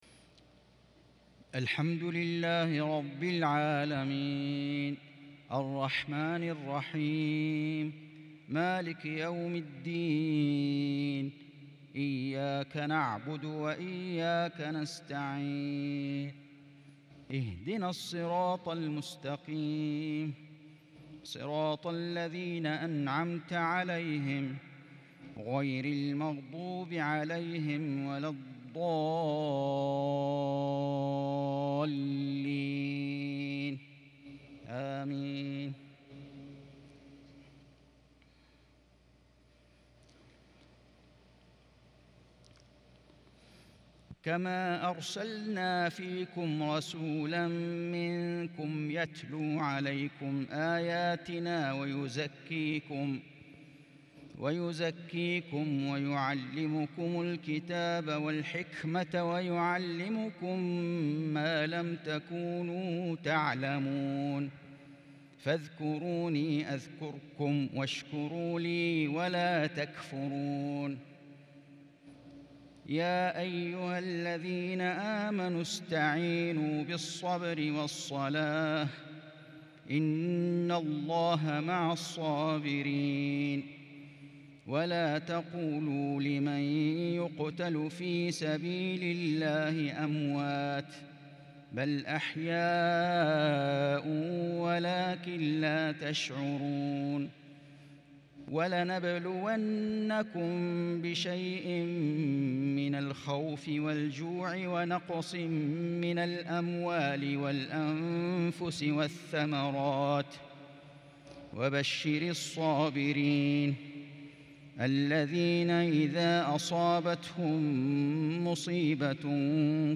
عشاء الإثنين 26 شوال 1442هـ من سورتي البقرة و غافر | Isha prayer from Surah Al-Baqara & Ghafir | 7-6-2021 > 1442 🕋 > الفروض - تلاوات الحرمين